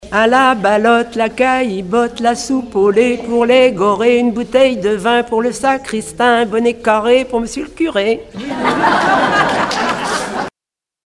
enfantine : jeu de balle
Regroupement de chanteurs du canton
Pièce musicale inédite